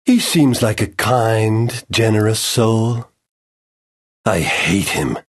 Hlášok je nepreberné množstvo a sú fakt kvalitne nadabované, intonácia a výber hlasov sedia presne do situácií, kedy postava povie svoju repliku.